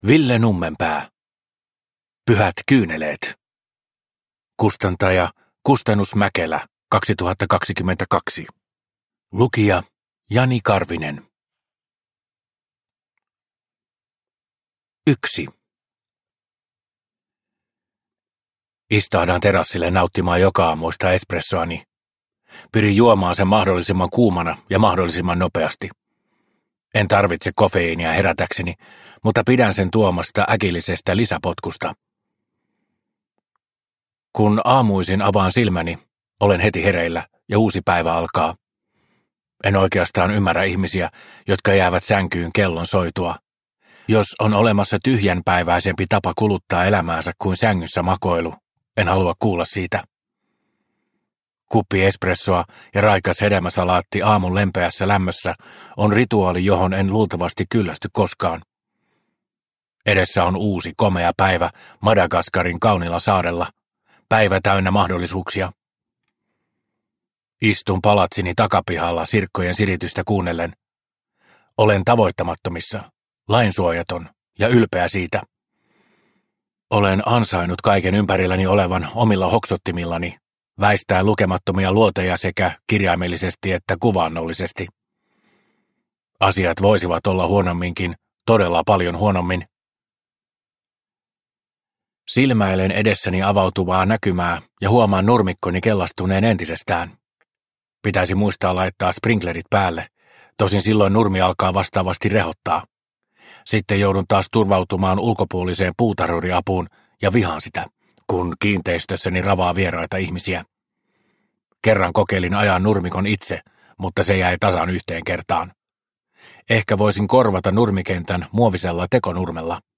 Pyhät kyyneleet – Ljudbok – Laddas ner